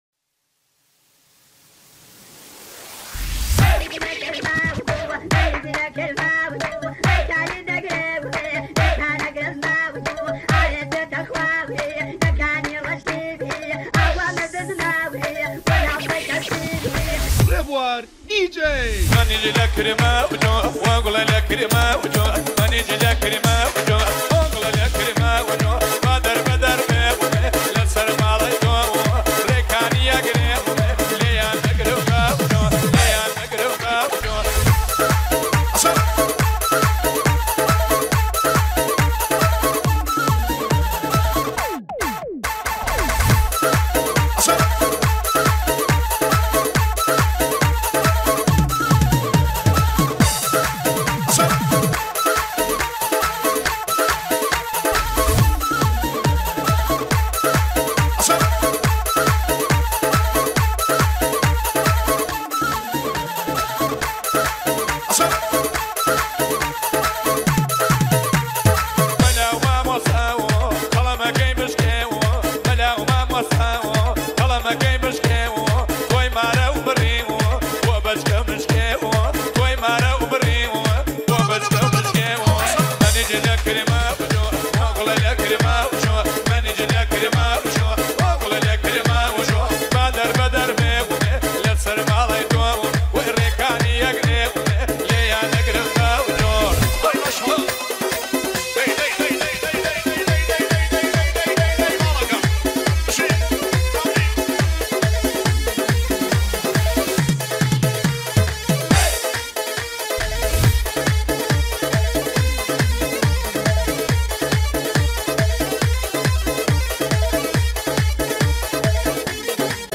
دانلود آهنگ کردی
گورانی شاد با ریمیکس مخصوص هلپرکه و رقص  :